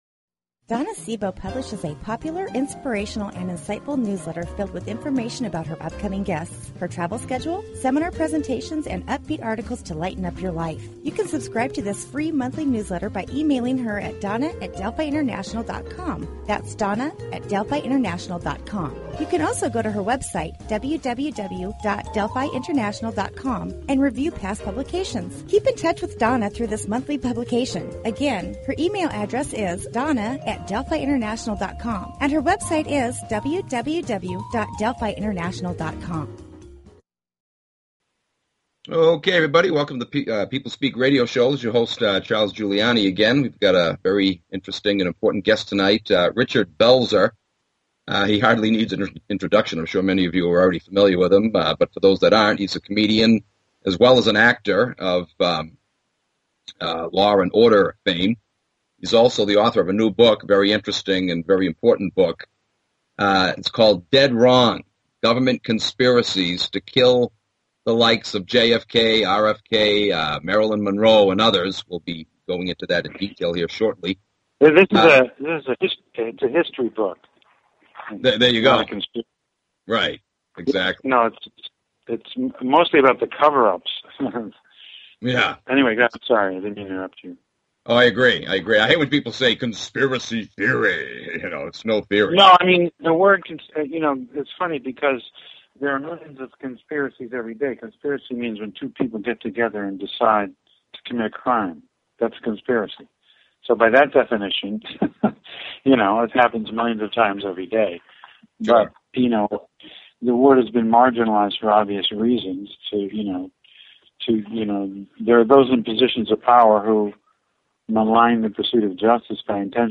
Guest, Richard Belzer
RICHARD BELZER is a stand-up comedian, actor and author.